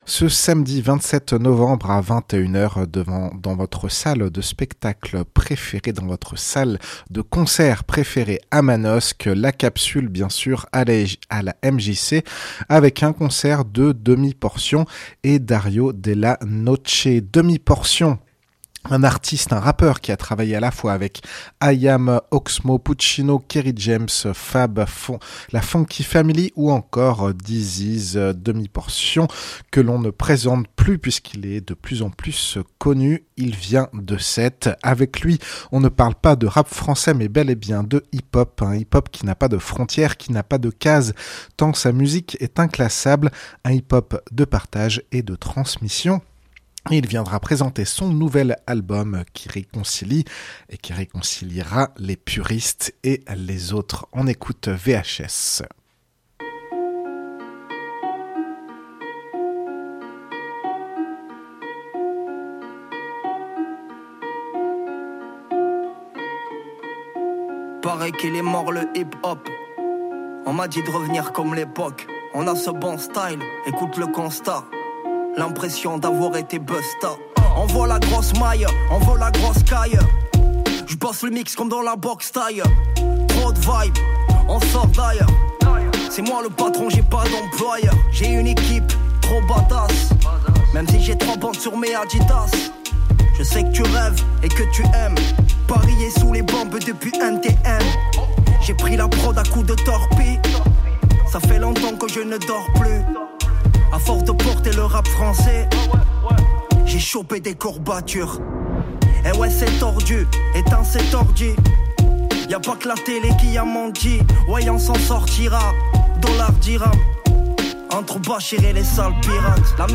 Brève